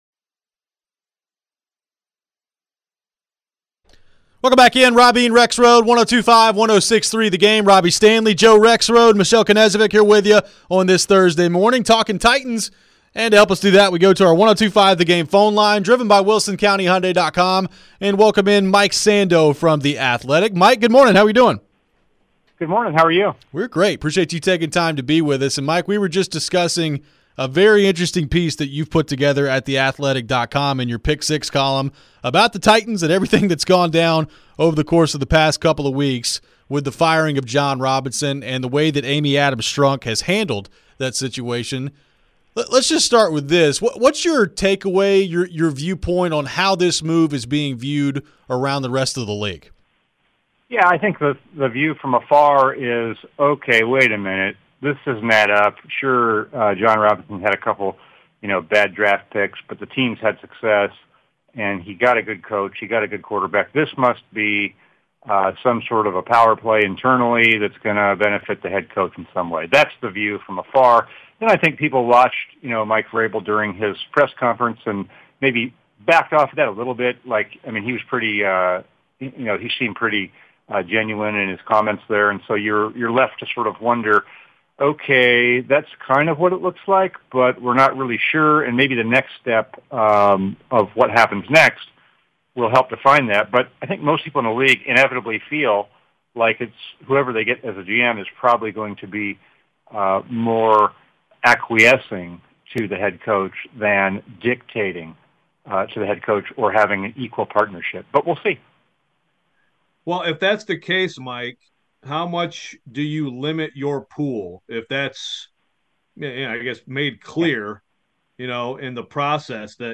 Interview with Mike Sando